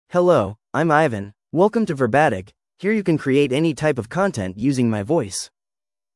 Ivan — Male English (United States) AI Voice | TTS, Voice Cloning & Video | Verbatik AI
MaleEnglish (United States)
Ivan is a male AI voice for English (United States).
Voice sample
Listen to Ivan's male English voice.
Ivan delivers clear pronunciation with authentic United States English intonation, making your content sound professionally produced.